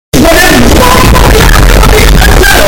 Very Loud Bomboclat